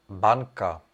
Ääntäminen
Ääntäminen Tuntematon aksentti: IPA: /bɑ̃k/ Haettu sana löytyi näillä lähdekielillä: ranska Käännös Ääninäyte Substantiivit 1. banka {f} 2. bankovnictví Suku: f .